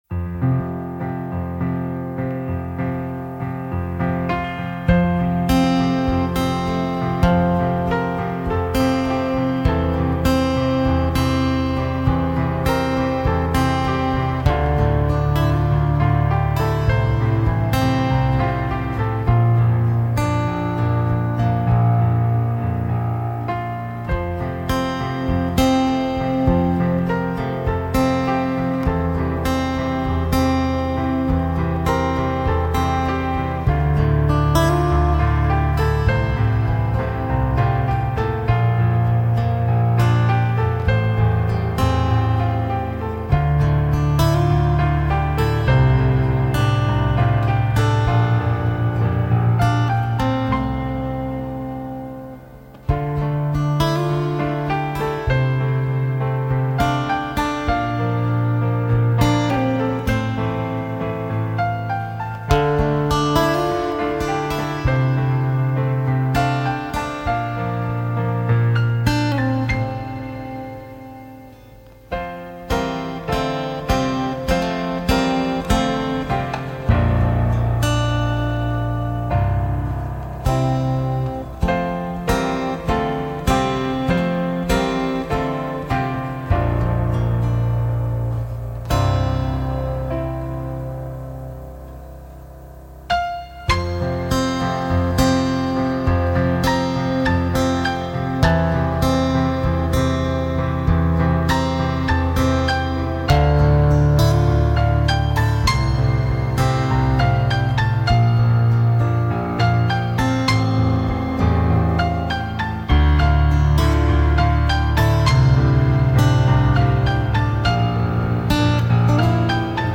"Children" Piano & Akustische Gitarre (original Komposition)
Ich habe mir mal erlaubt, den Audiopart des Videos zu rippen und etwas nachzubearbeiten.
Den Bereich von 1000 Hz bis 4000 Hz habe ich getamed und um einige Db abgesenkt, sowie den Höhenbereich enhanced und um ein Paar Db angehoben. Den Bassbereich unterhalb 200 Hz habe ich mit MSW2 Mono gemacht.